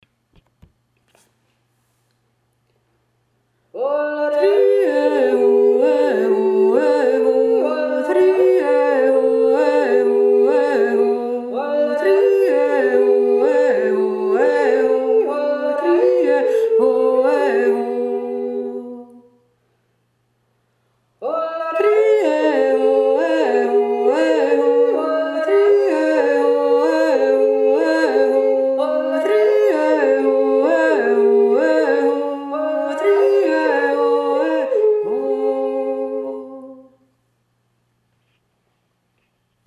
Goldegg jodelt März 2024
Dirndljodler
beide Stimmen